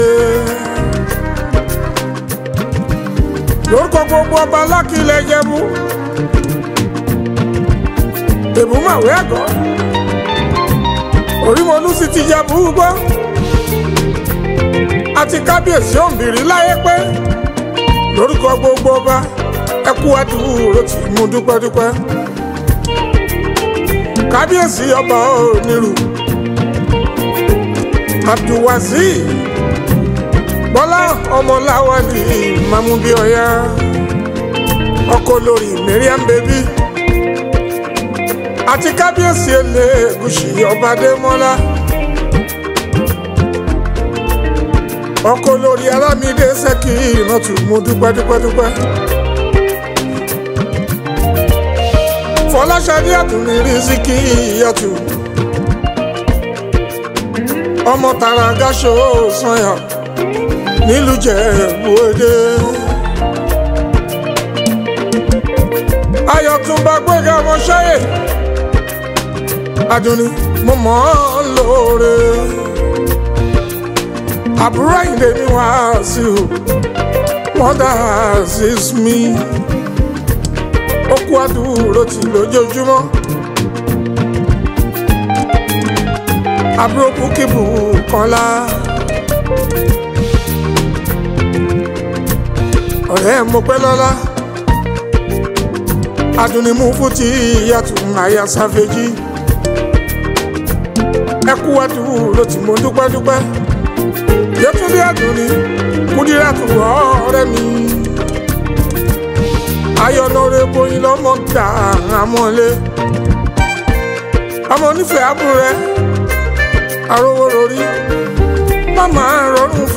Fuji, Highlife
Nigerian Yoruba Fuji track
most emotional-packed track